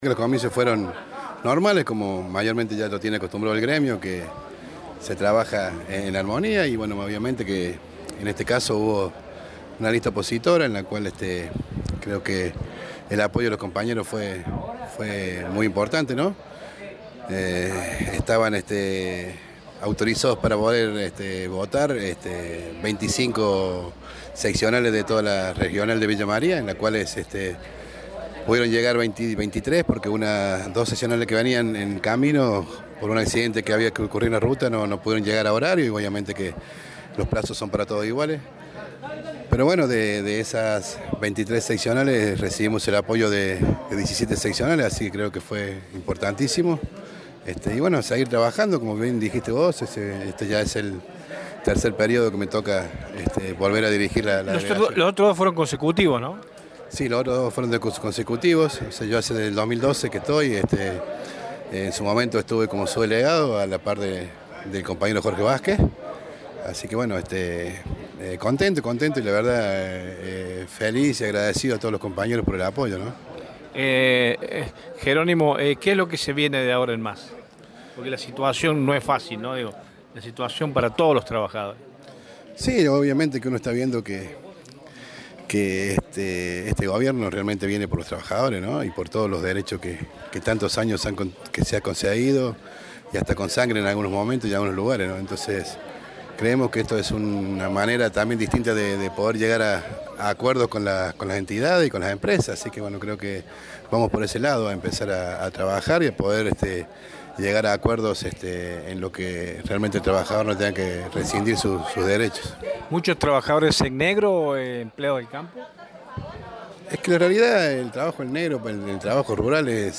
En diálogo con RadioShow